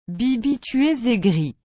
translate_tts_6.mp3